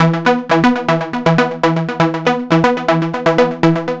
TSNRG2 Lead 015.wav